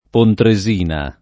[ pontre @& na ]